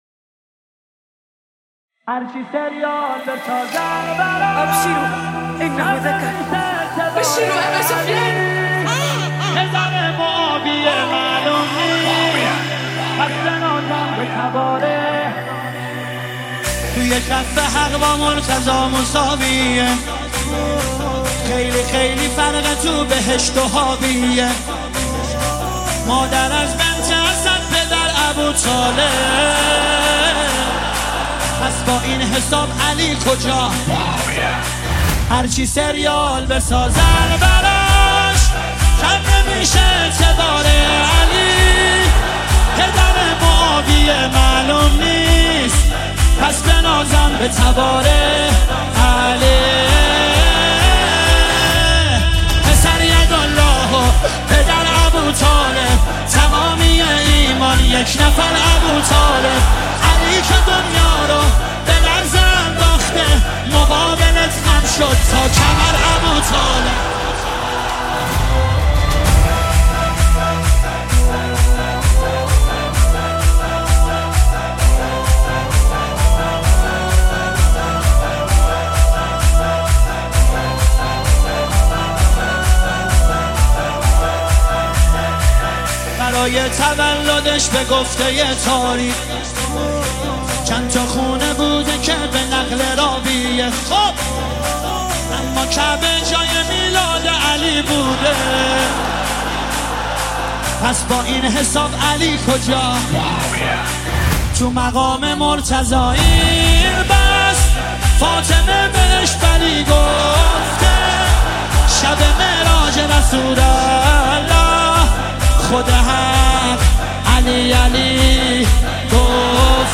مداح نوحه